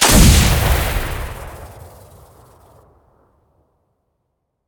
sniper1.ogg